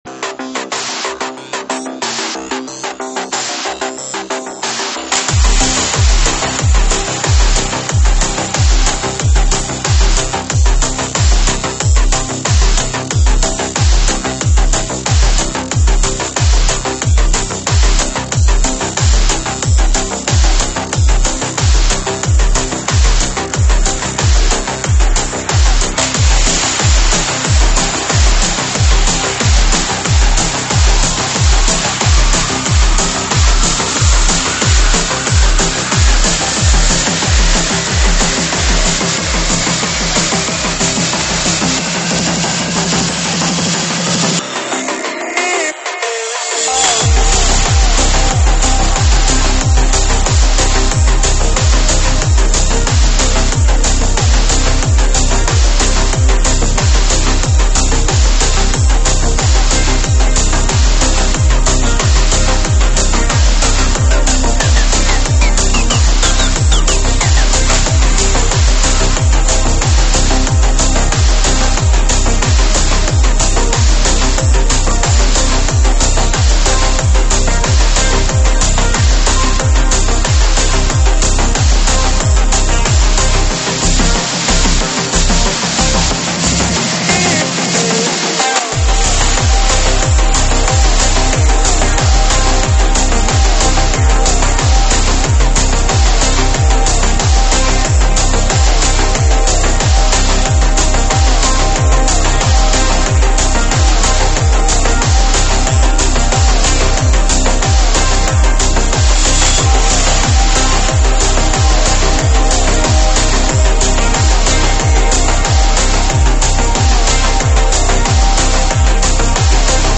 栏目：慢摇舞曲